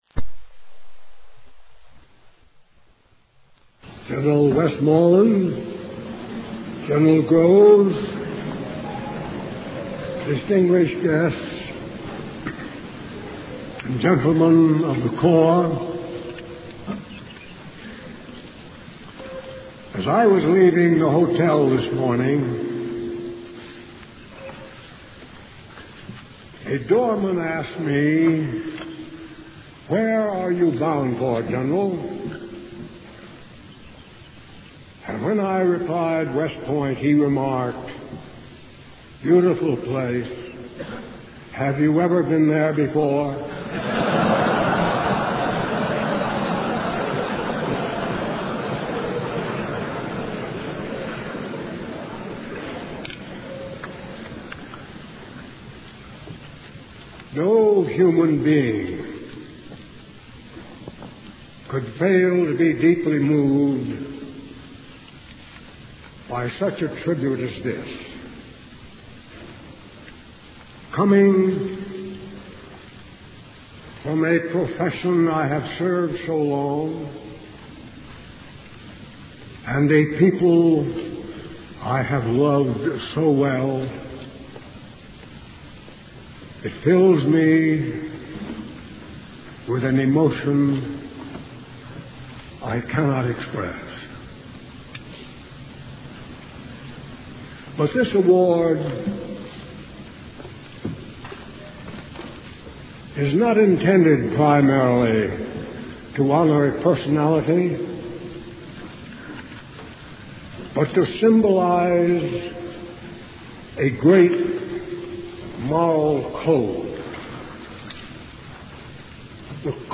General Douglas MacArthur: Thayer Award Acceptance Address
delivered 12 May 1962, West Point, NY